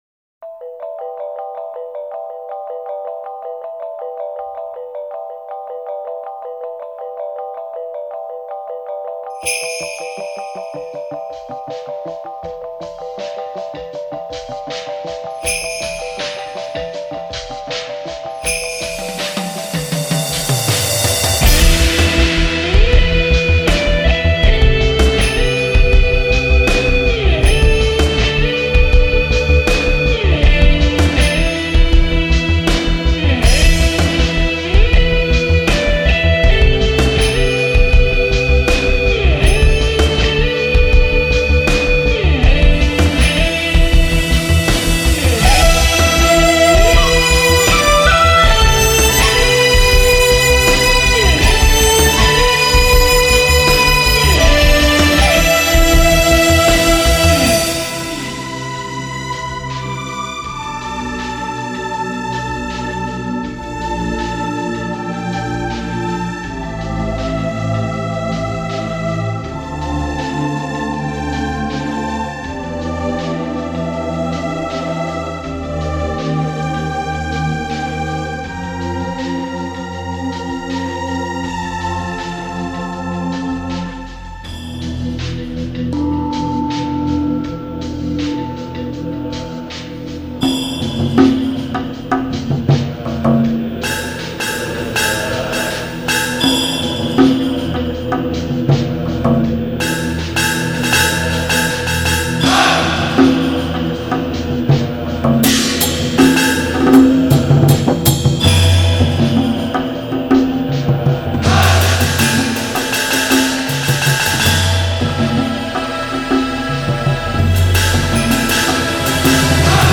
磅礴旋律加上中国古乐器交织而出的新融合音乐